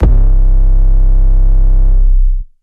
REDD 808 (24).wav